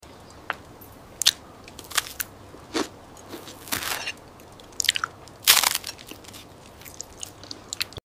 In this ASMR video, Queen Cleopatra dines in silence — figs, roasted fish, honeyed bread, and cheese fill the royal table. No words are spoken. No water is poured. Only the delicate, natural sounds of eating in a dimly lit palace chamber remain. Let the soft crackle of oil lamps and the calm gestures of a queen guide your senses.